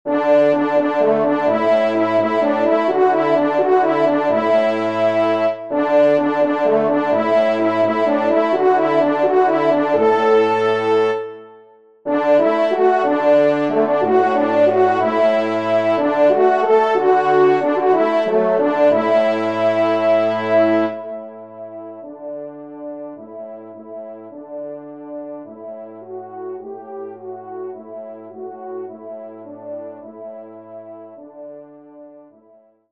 Genre :  Divertissement pour Trompes ou Cors
4ème Trompe